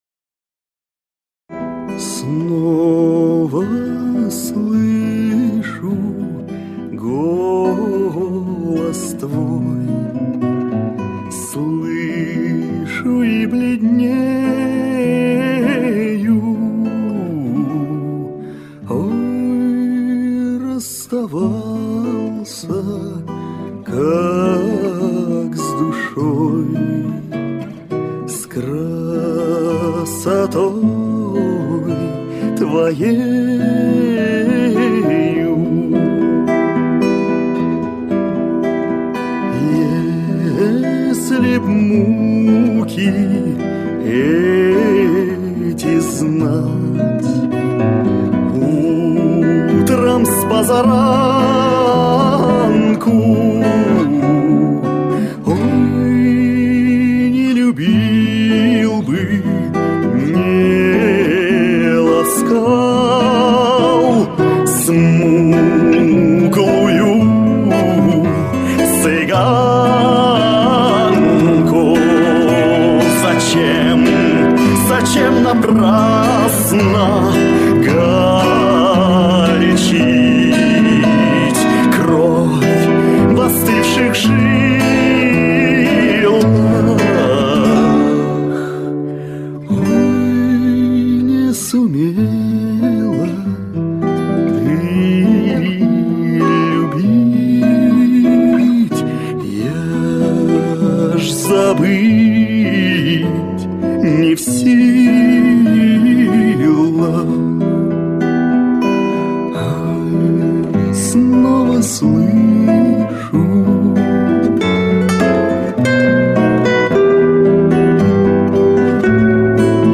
В этом фильме звучит еще такой красивый романс